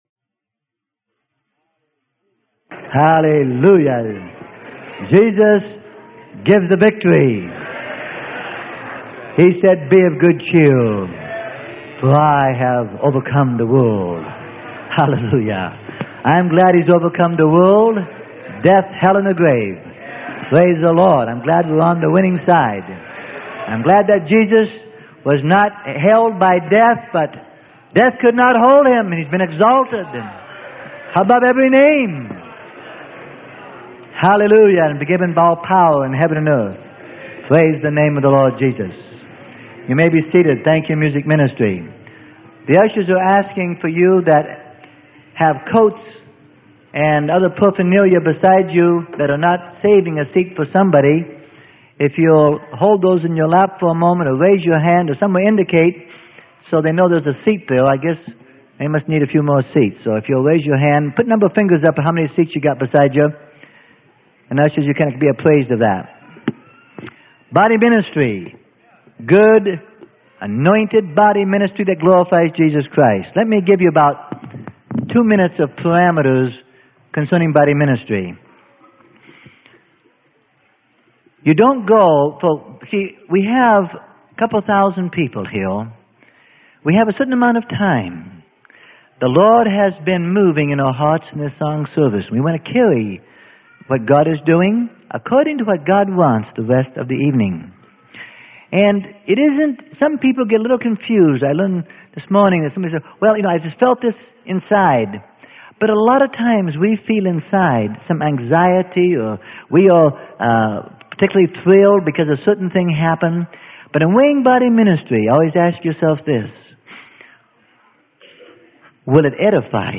Sermon: Relationships - Exhortations - Who is This?